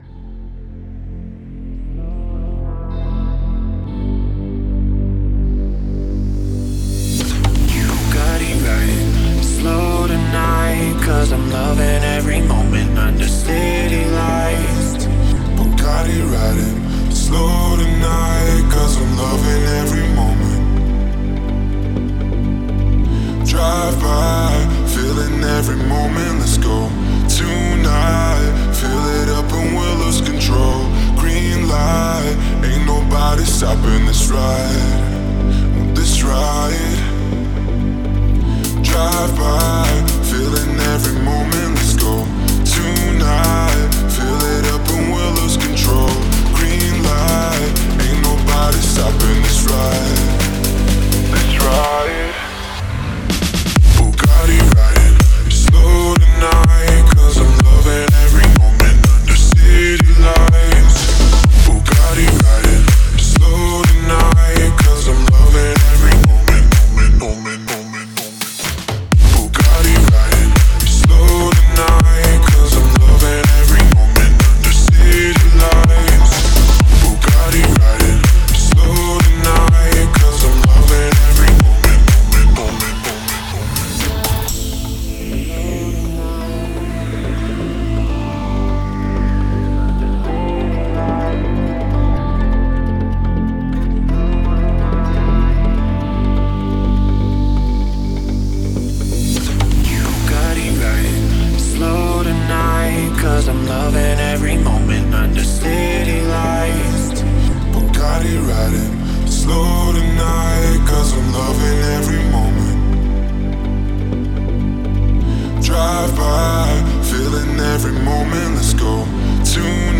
это энергичная трек в жанре EDM